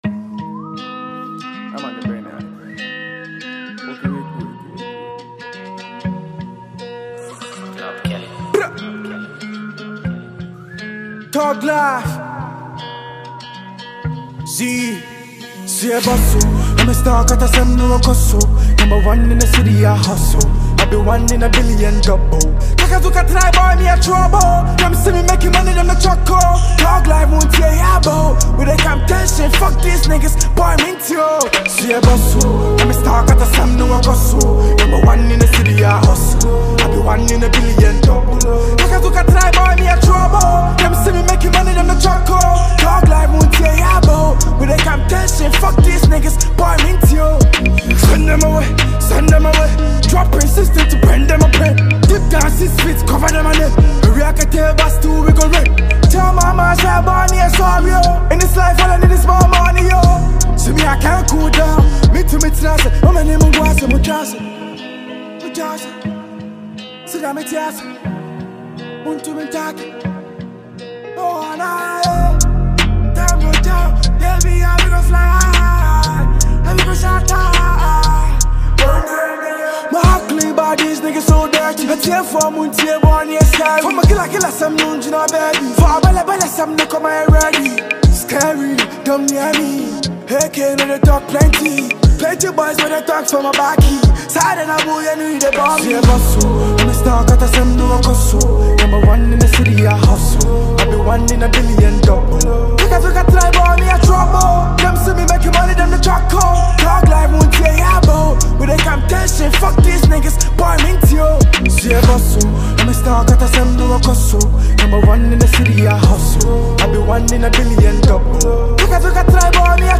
Ghanaian rapper
drill anthem